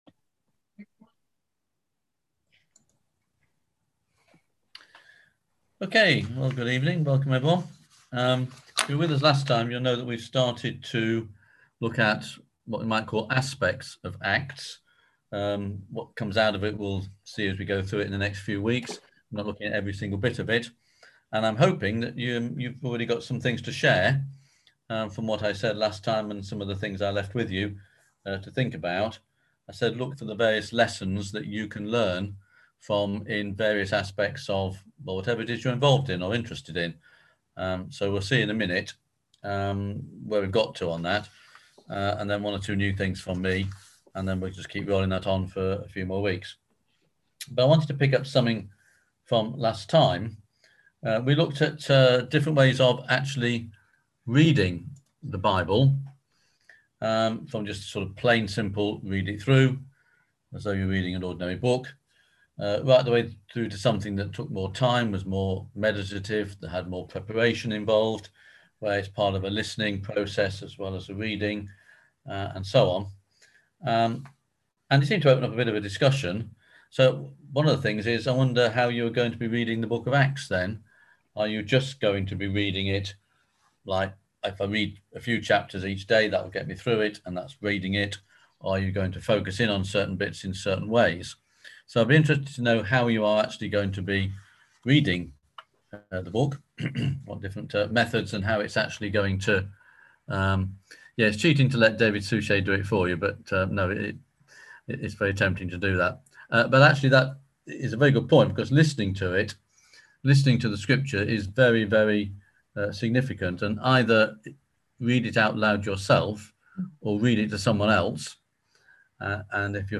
On September 30th at 7pm – 8:30pm on ZOOM